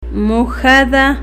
Phonological Representation ma'xada